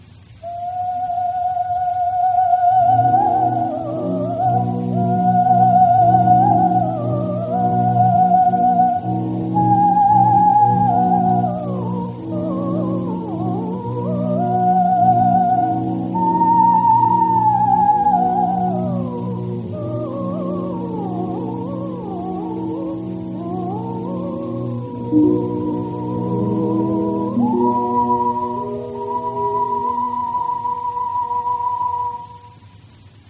Und so klang dieses vielfach über den Sender gespielte Lied damals: